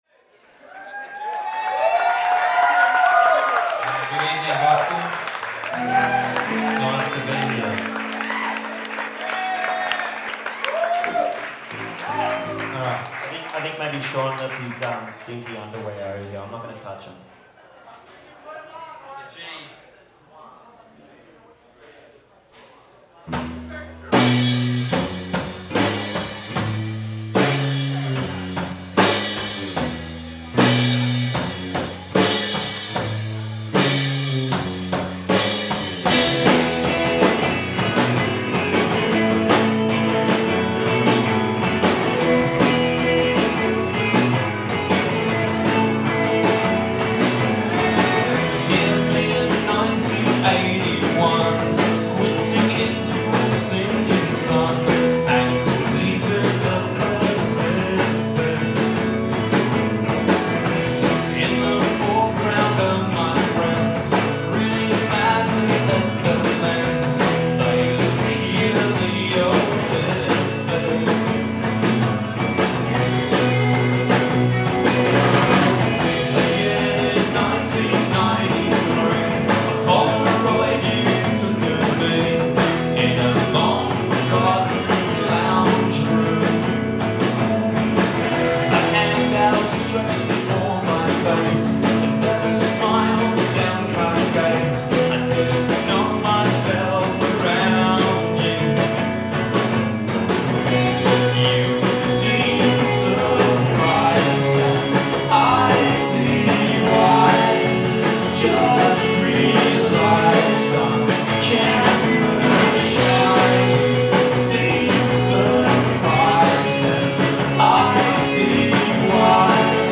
Today we have a live recording of